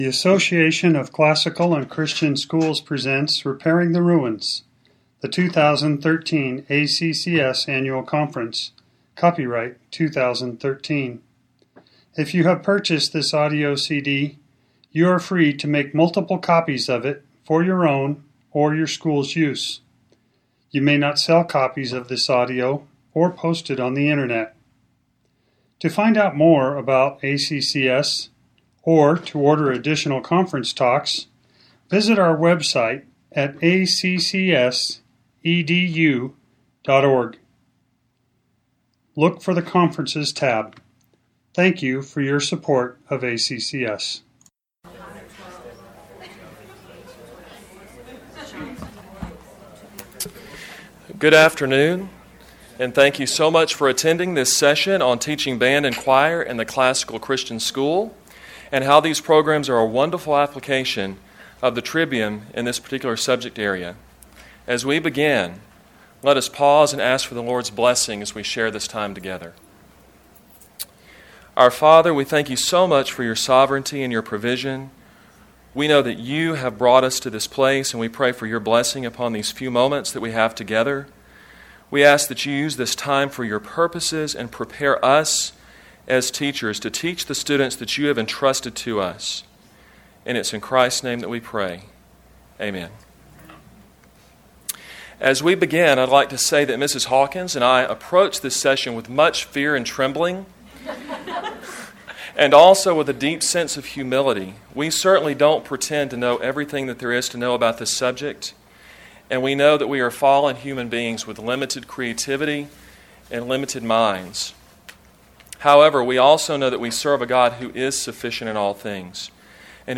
2013 Workshop Talk | 0:50:04 | All Grade Levels, Art & Music
The Association of Classical & Christian Schools presents Repairing the Ruins, the ACCS annual conference, copyright ACCS.